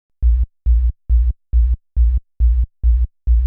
Index of /90_sSampleCDs/Best Service ProSamples vol.54 - Techno 138 BPM [AKAI] 1CD/Partition C/SHELL CRASHE
NOIZY BASS-L.wav